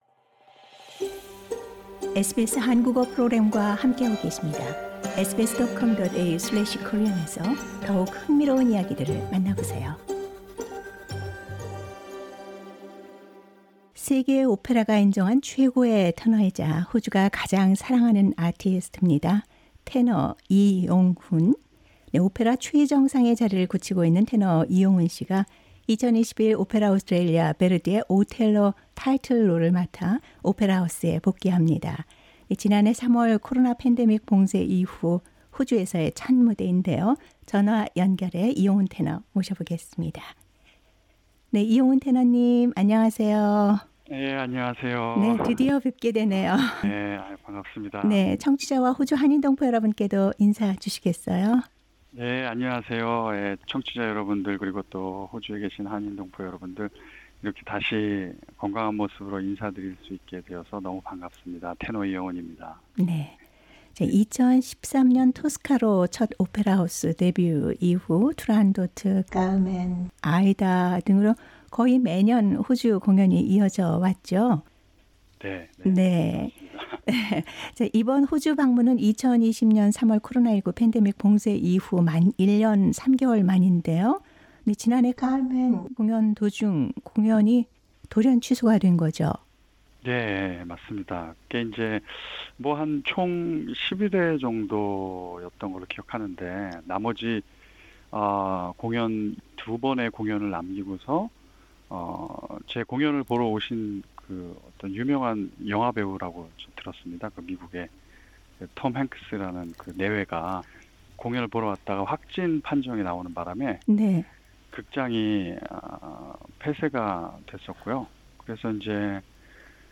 [인터뷰] 세계 독보적 리리코 스핀토 테너 이용훈, ‘오텔로’로 호주 롤 데뷔